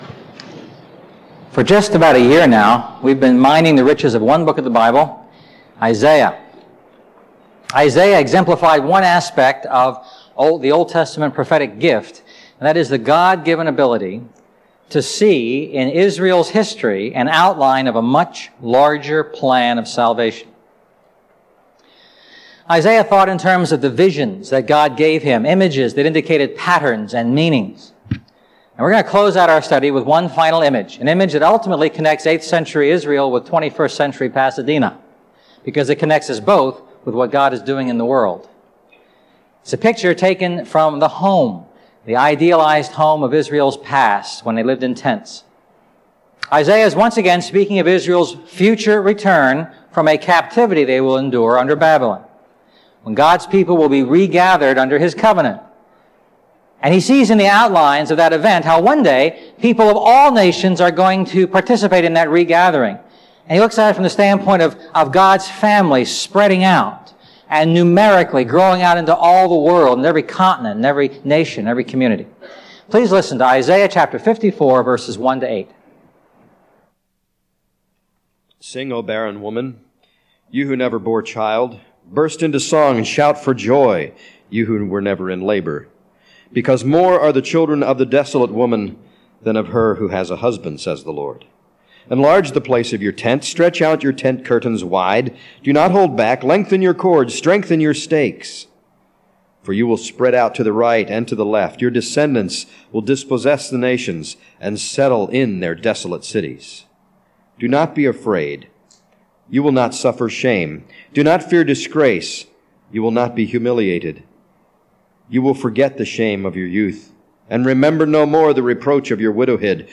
A message from the series "The Lord Saves."